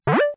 debian_drop.wav